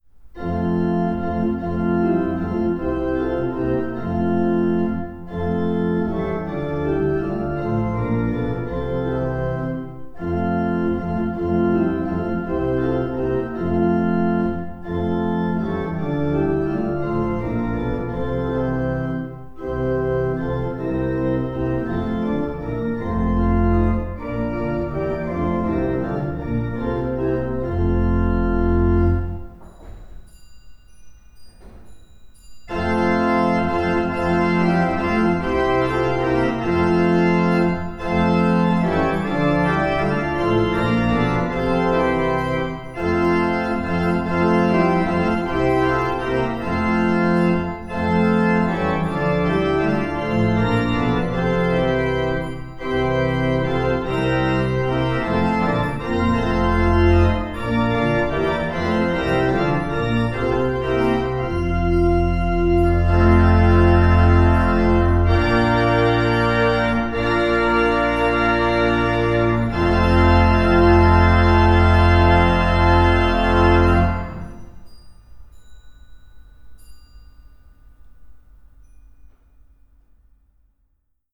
Audio Orgel
Orgel.mp3